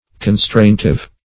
Search Result for " constraintive" : The Collaborative International Dictionary of English v.0.48: Constraintive \Con*straint"ive\, a. Constraining; compulsory.